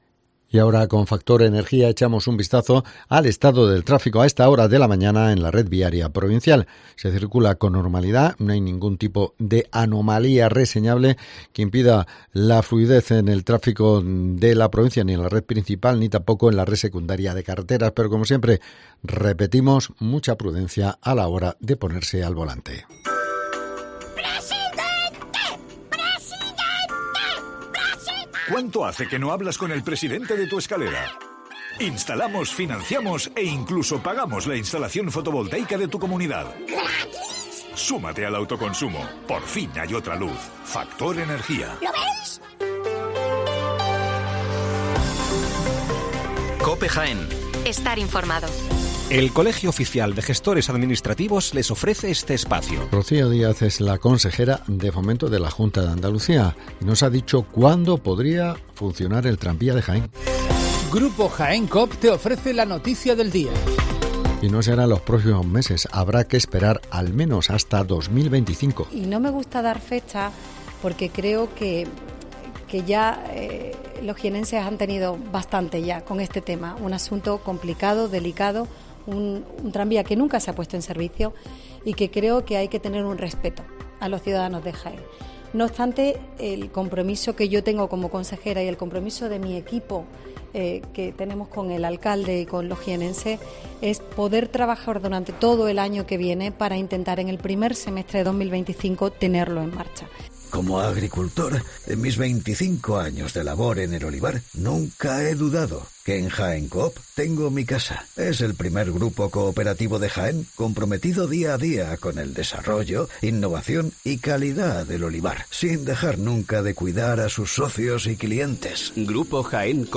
AUDIO: Las noticias locales